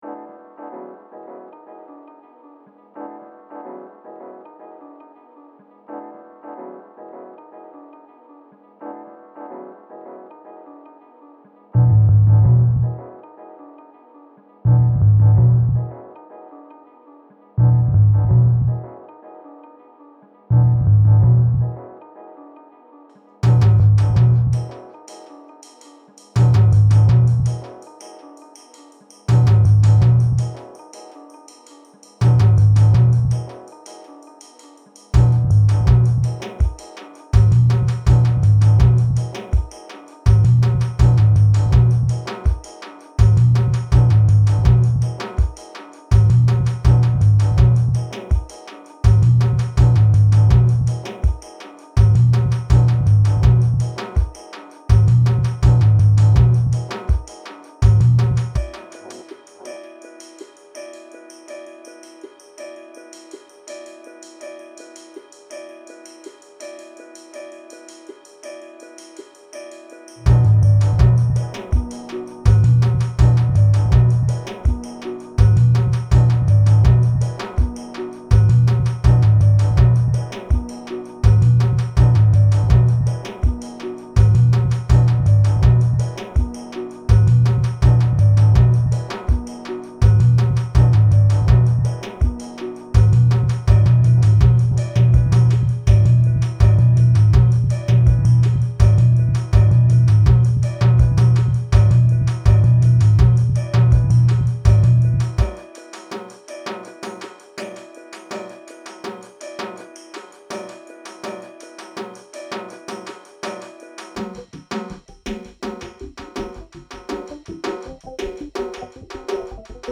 All sounds taken from Mystic John, all done inside Octatrack. Bouncedown was into Logic Pro for some final mastering EQ and compression.
That kick is moving so much air what are doing to it??
It’s mostly the OT filter with a high Q being modulated by fast EG and then going into the OT compressor.
When those horns hit felt good